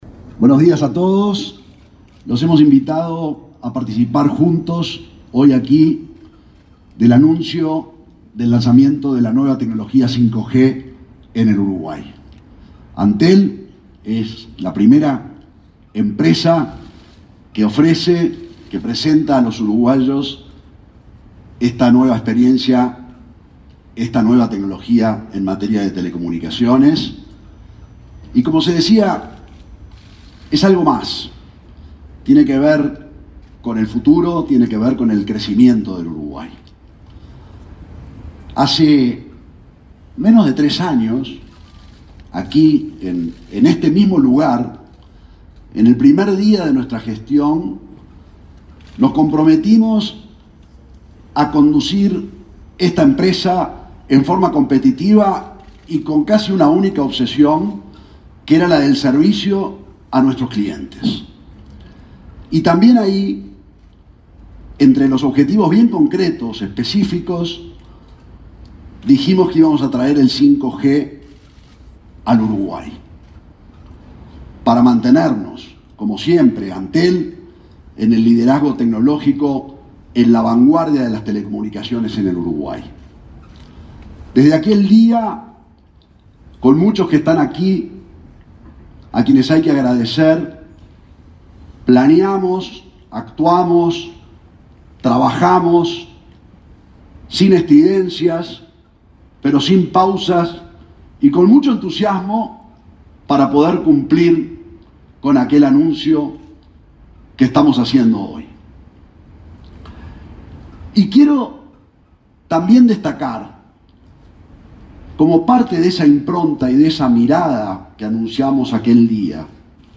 Palabras del presidente de Antel, Gabriel Gurméndez
El presidente de Antel, Gabriel Gurméndez, participó en el lanzamiento de tecnología 5G en Uruguay del organismo.